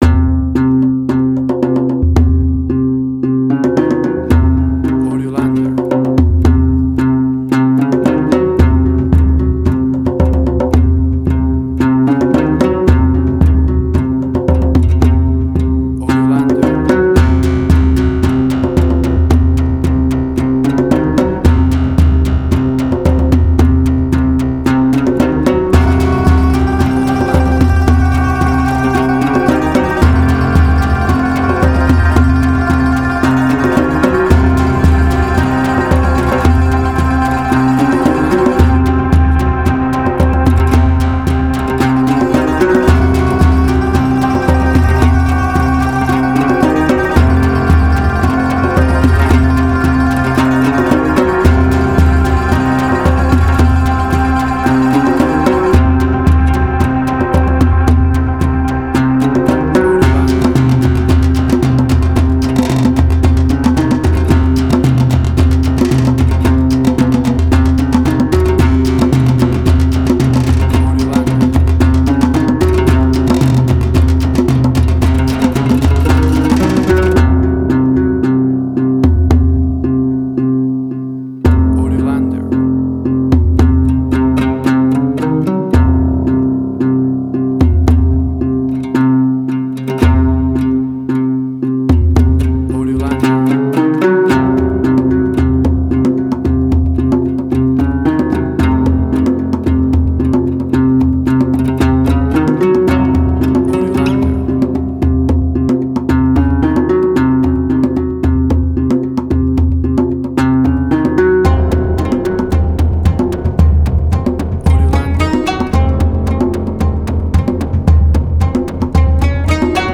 Middle Eastern Fusion
Tempo (BPM): 112